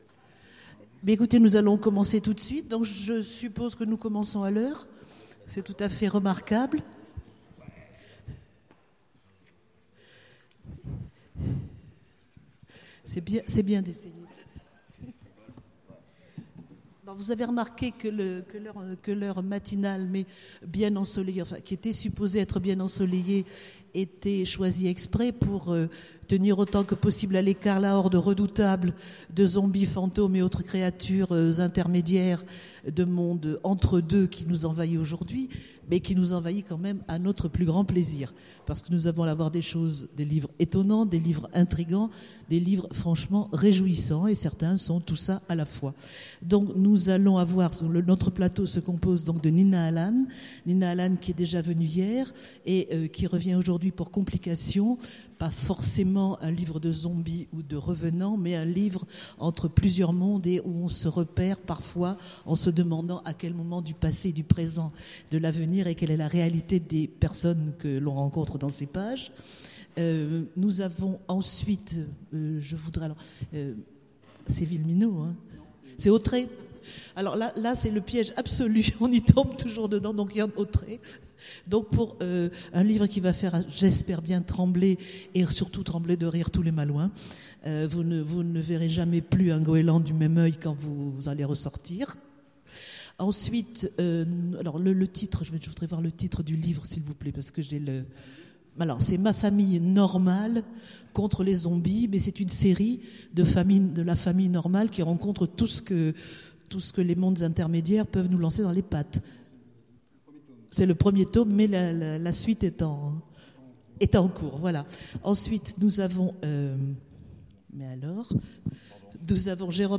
Étonnants Voyageurs 2015 : Conférence Zombies, fantômes et autres créatures de l'ombre